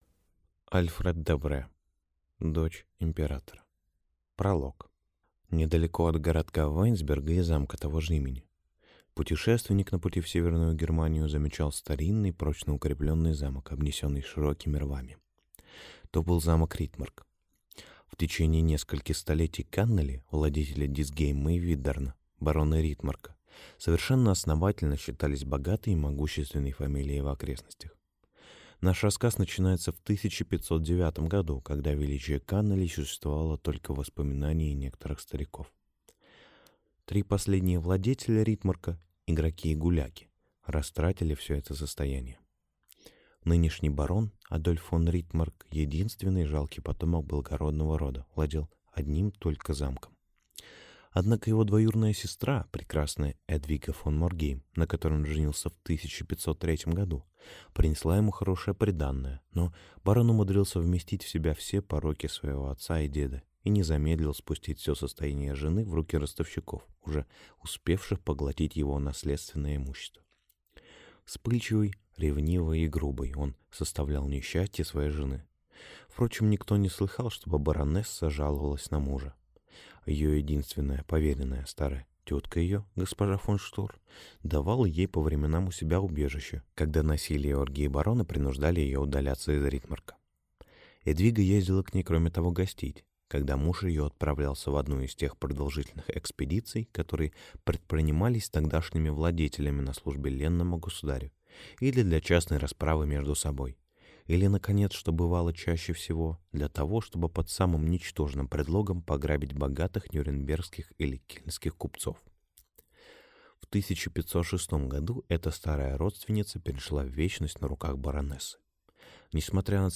Аудиокнига Дочь императора | Библиотека аудиокниг